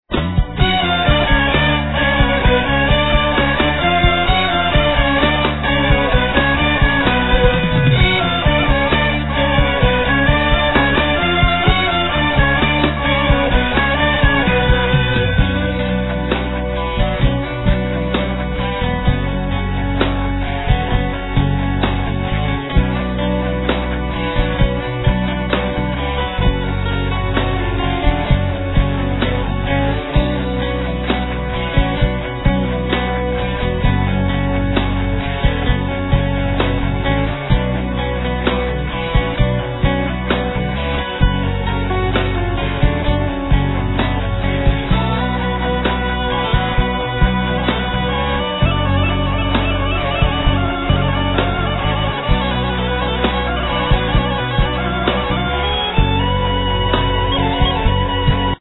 Musicians
Vocals, Bass
Flute, Turk-pipe